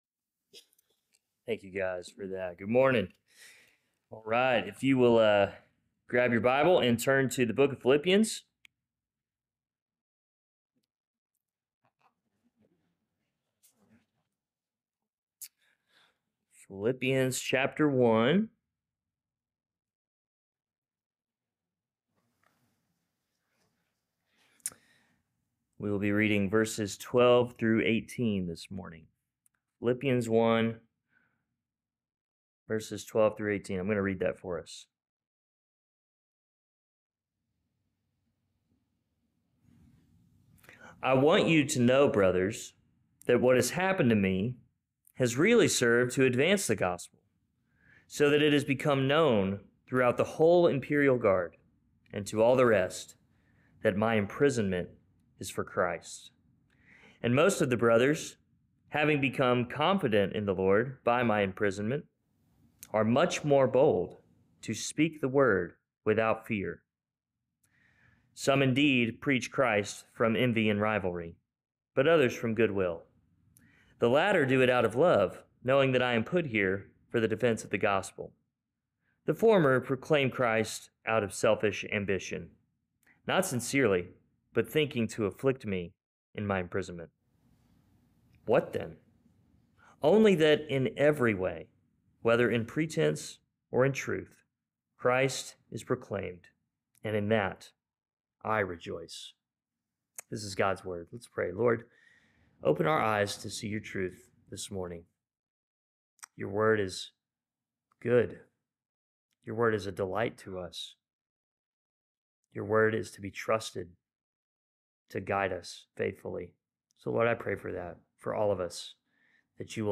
Sermons | Mascot Baptist Church